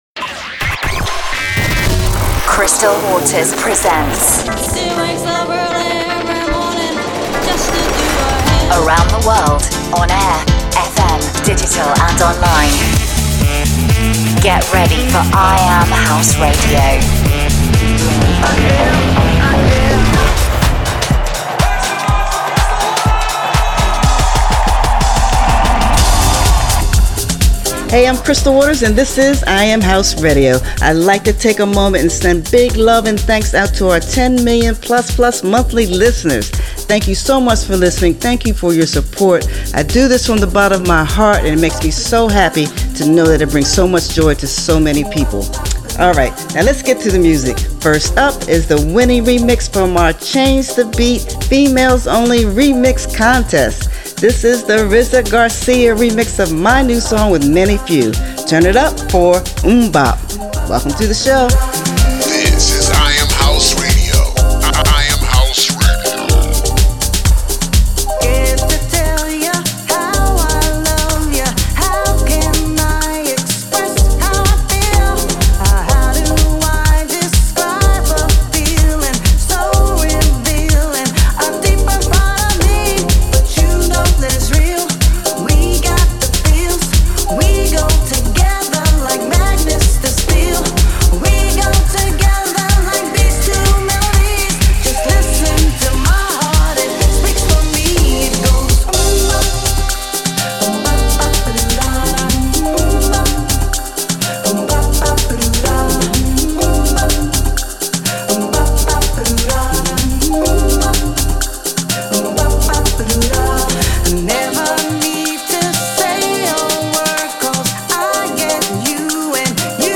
Playing the best new House Music from around the world.